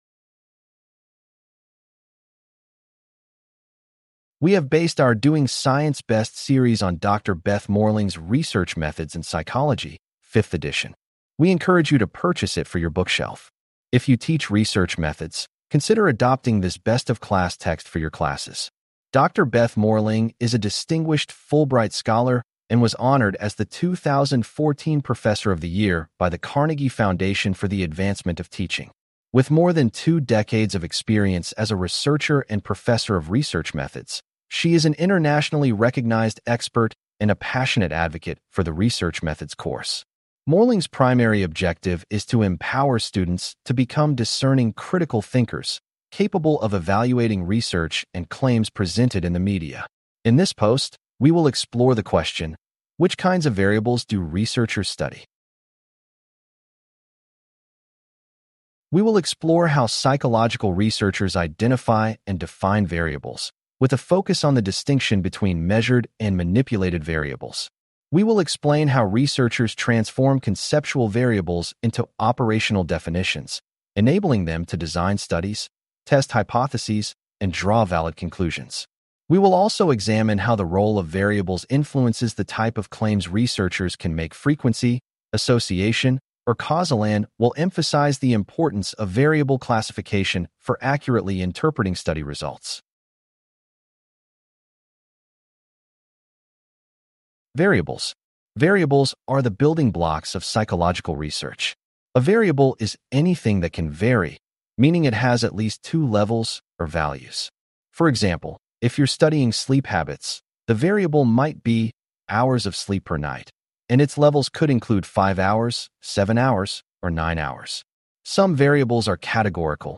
CLICK TO HEAR THIS POST NARRATED We will explore how psychological researchers identify and define variables, focusing on the distinction between measured and manipulated variables.